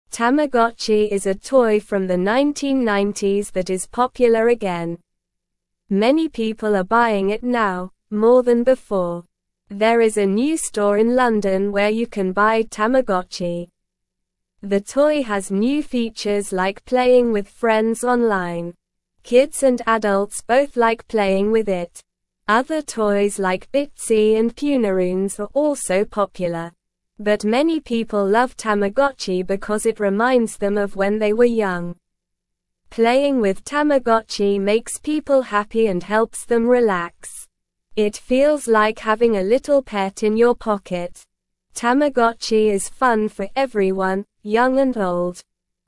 Slow
English-Newsroom-Beginner-SLOW-Reading-Tamagotchi-Toy-Makes-People-Happy-and-Relaxed.mp3